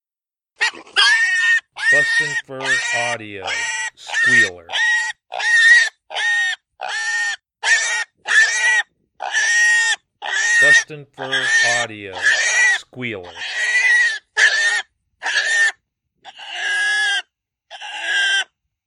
Juvenile hog in full distress. Used for calling hogs or predators.